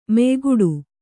♪ meyguḍu